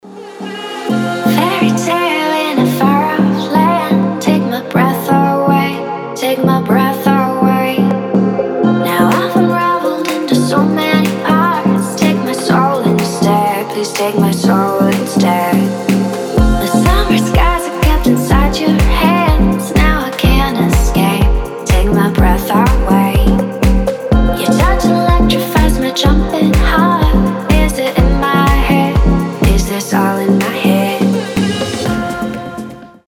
deep house
восточные мотивы
скрипка
красивый женский голос